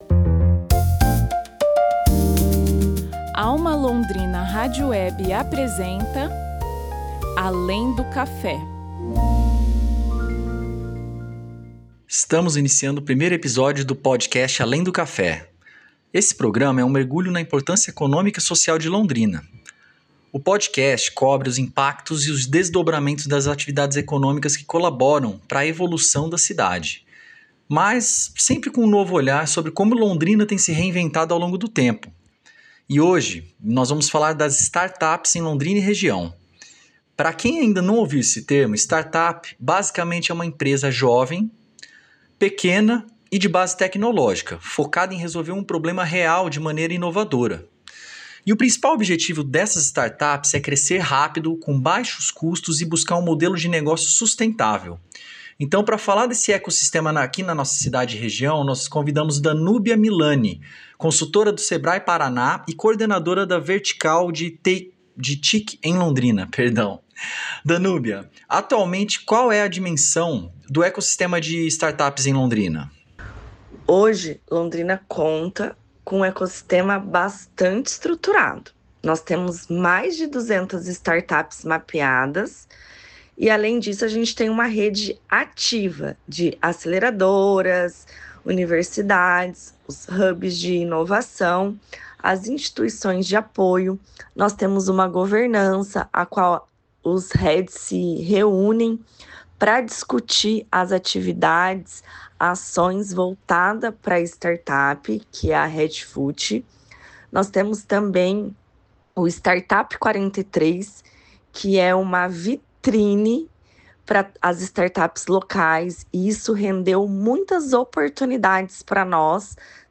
Jornalismo